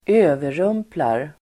Uttal: [²'ö:verum:plar]